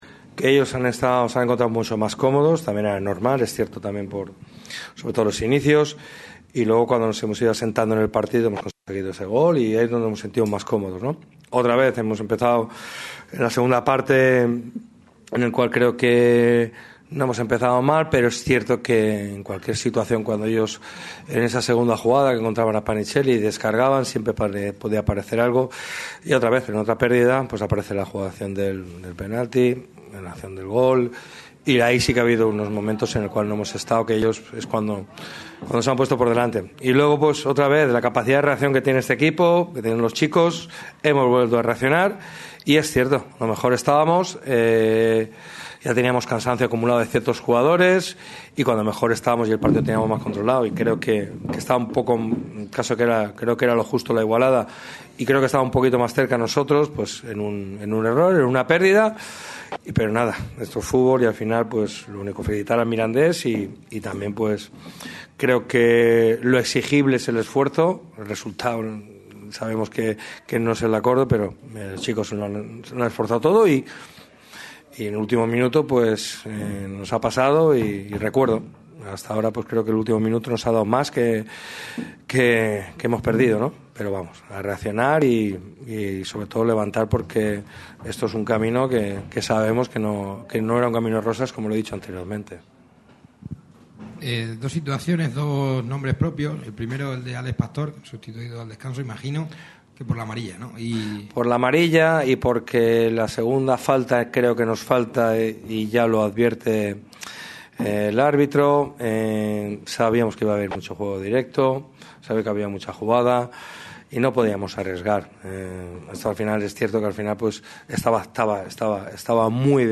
El entrenador del Málaga CF, Sergio Pellicer, ha comparecido ante los medios en sala de prensa tras la derrota en el último minuto ante el CD Mirandés en Anduva. El técnico de Nules ha realizado su valoración del partido, al rival y ha repasado varios nombres propios como el de Pastor, Larrubia o Galilea, que termina muy señalado en la acción del último gol que otorgó la victoria a los locales.